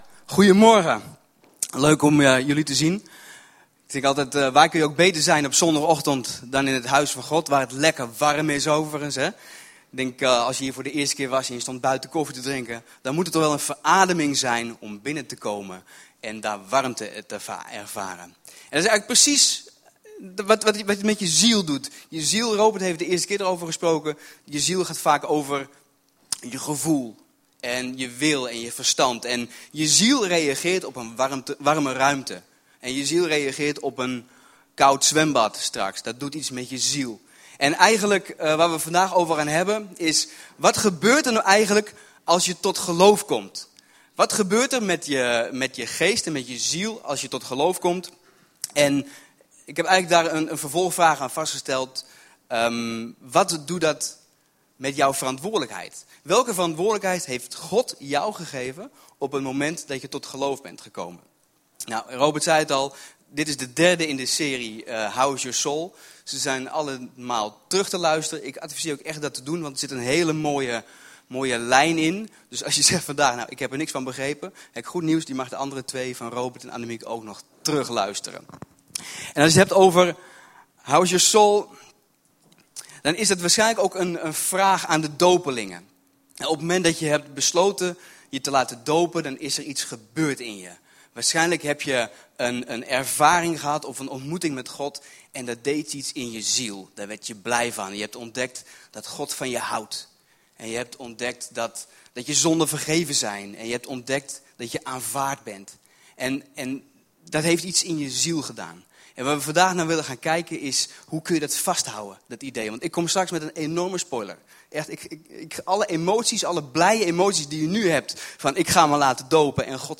2018_11_18_doopdienst | LEEF!